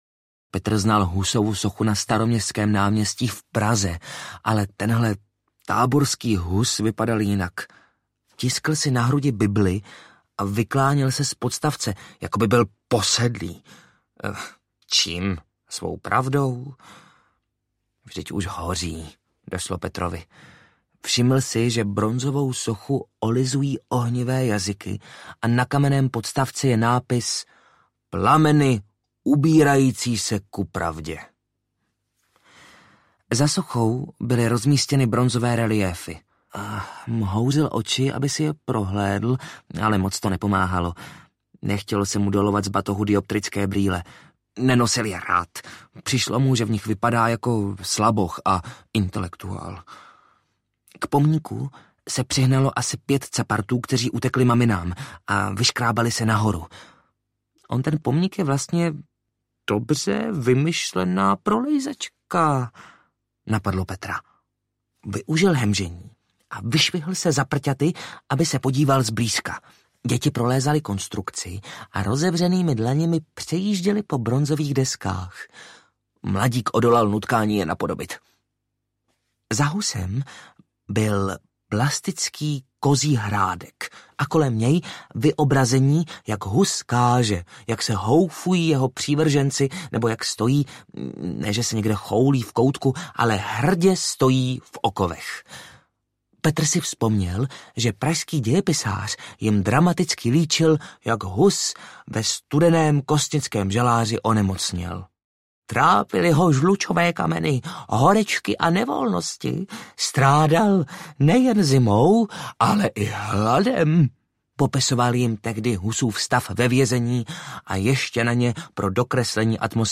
H. U. S. (Hus úplně světovej) audiokniha
Ukázka z knihy
Vyrobilo studio Soundguru.